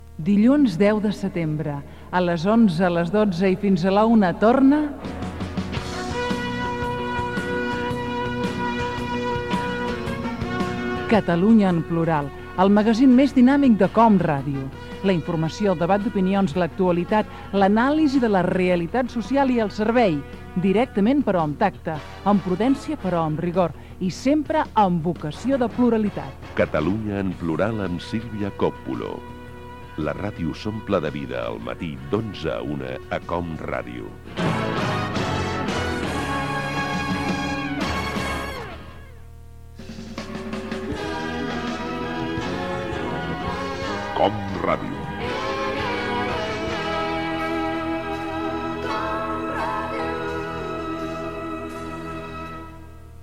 Promoció del primer programa de la temporada 2001/2002 i indicatiu emissora